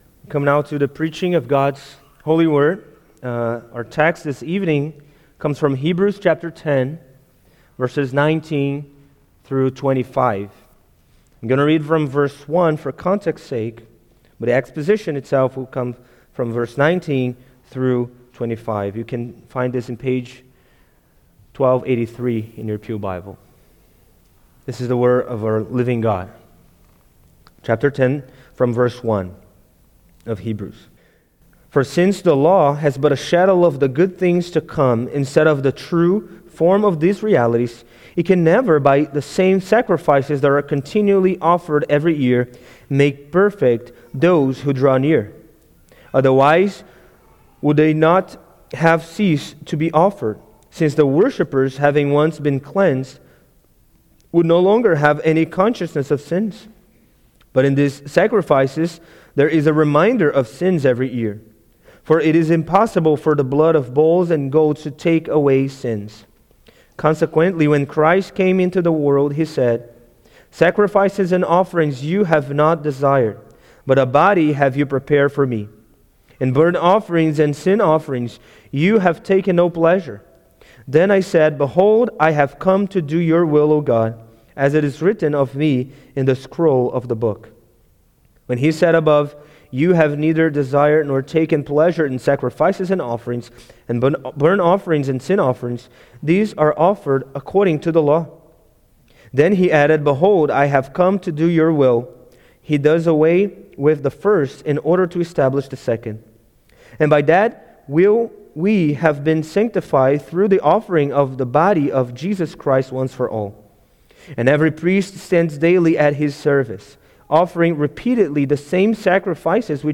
New Year's Day Sermons